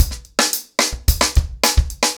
TimeToRun-110BPM.23.wav